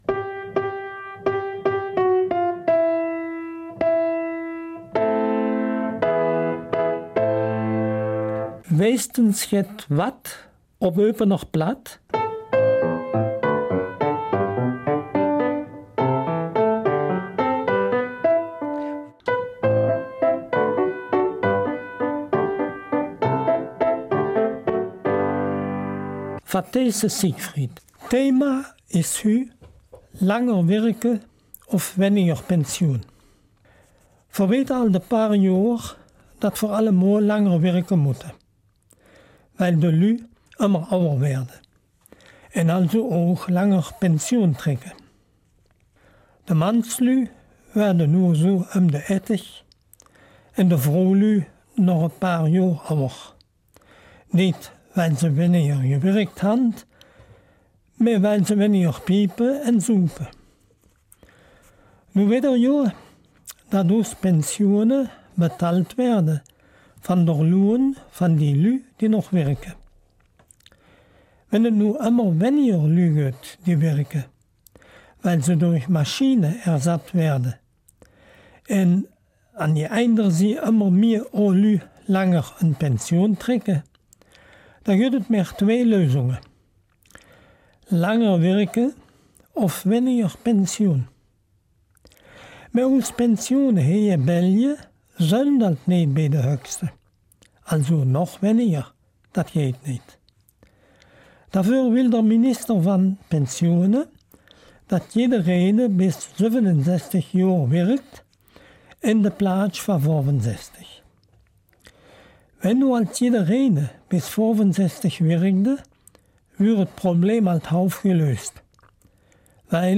November 2018 Eupener Mundart Dein Browser unterstützt kein Audio-Element.